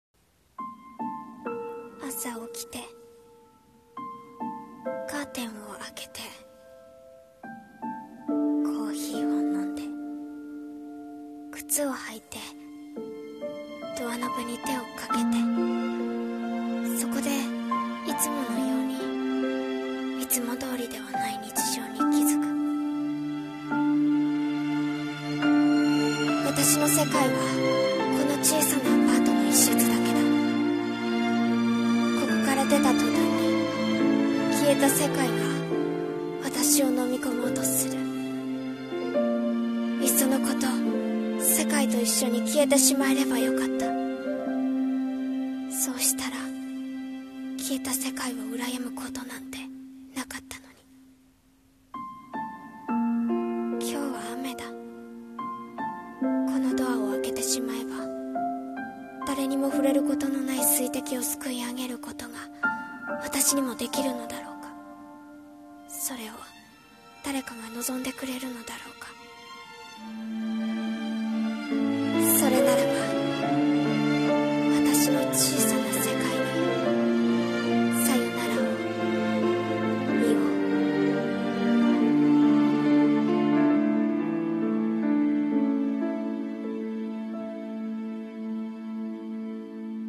【一人声劇】消えた世界にゆれる【朗読】